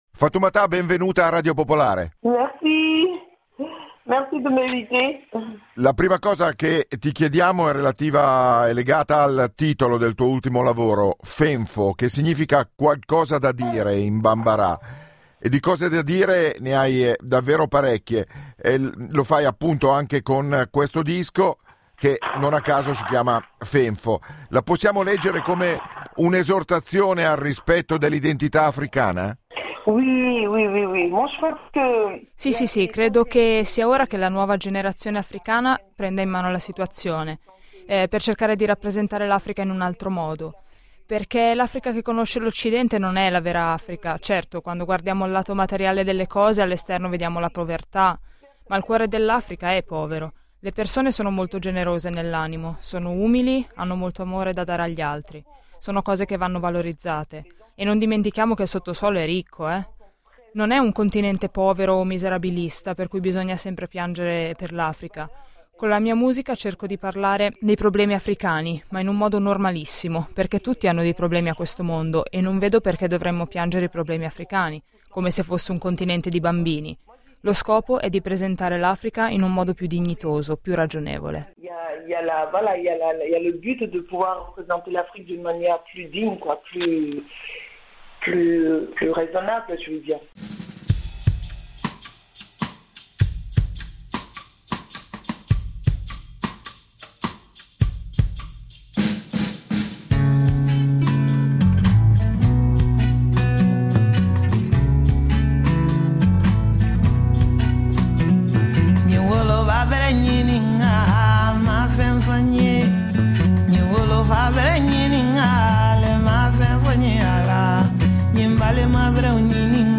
Qui l’audio integrale dell’intervista: